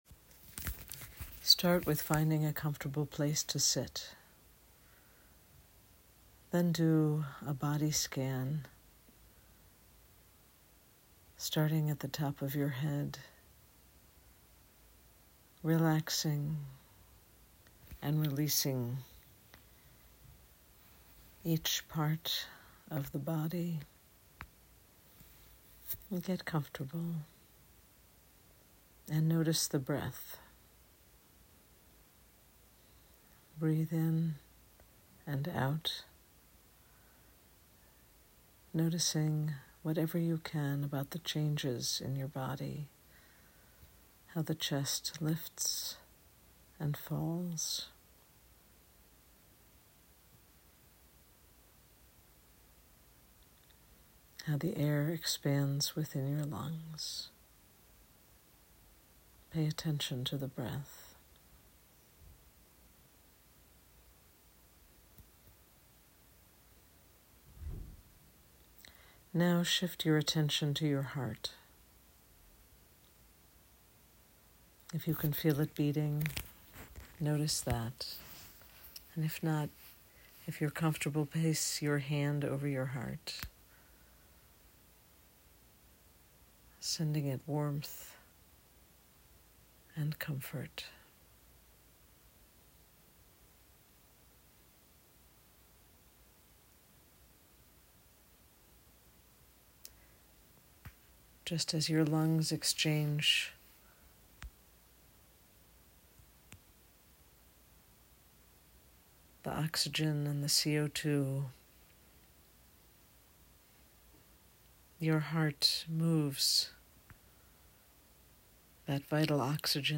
You can listen to this guided meditation here: